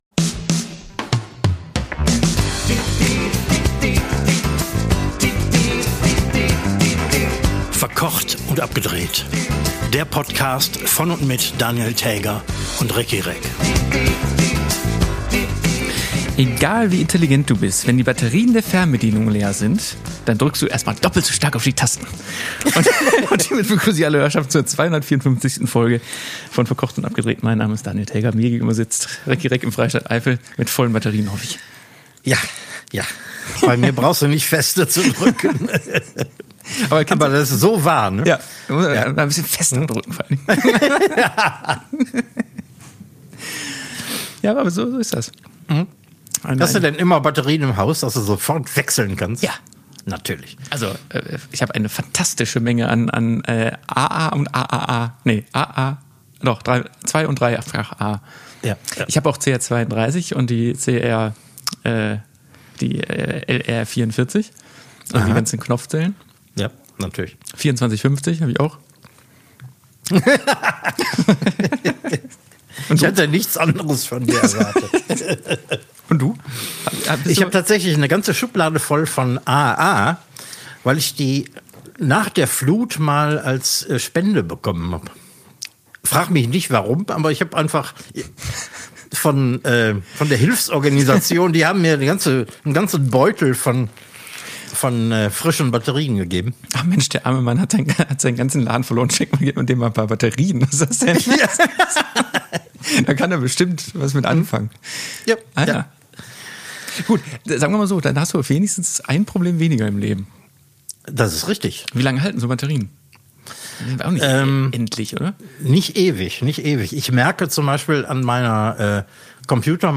Völlig außer Atem und mit zerzaustem Zeitmanagement treffen Koch und Fernsehmann heute auf den letzten Drücker für die 254. Folge von „Verkocht und Abgedreht“ am Mikro zusammen.